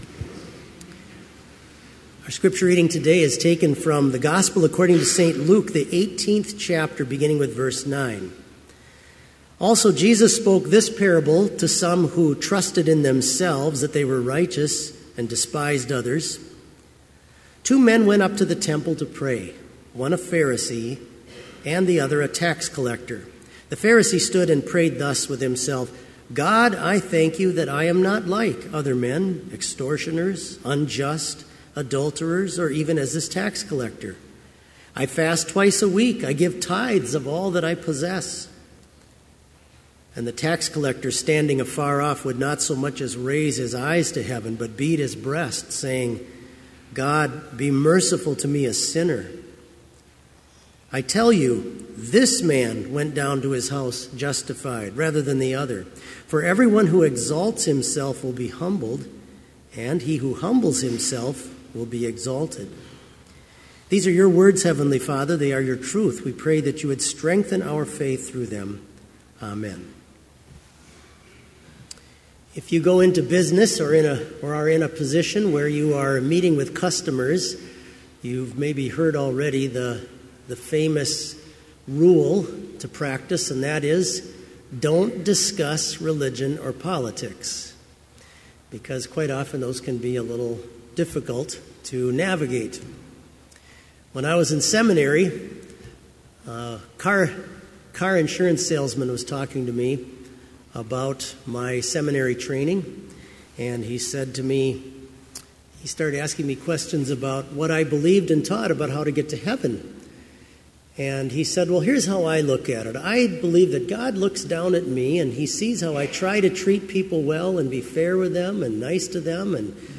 Complete service audio for Chapel - February 13, 2018
Prelude Versicles, Gloria Patri & Confession of Sin Reading: Luke 18:9-14 Devotion The Responsory, Collect and Canticle Hymn In Christ Alone Postlude